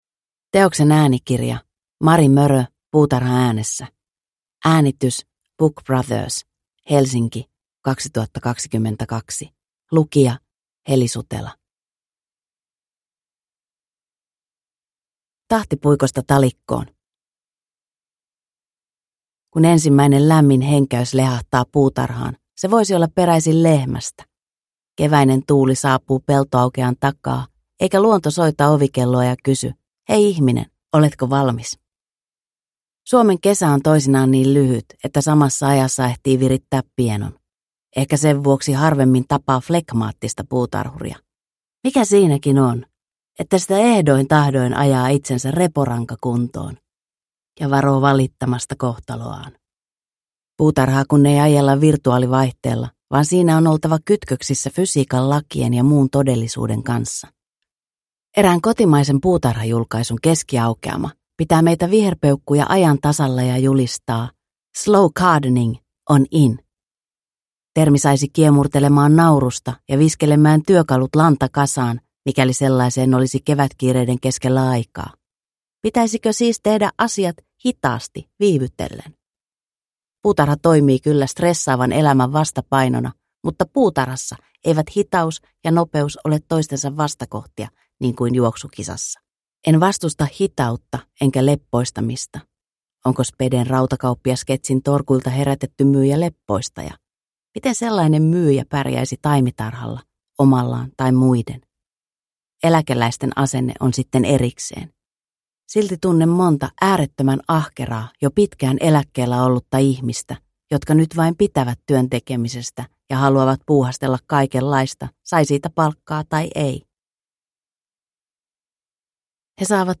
Puutarha äänessä – Ljudbok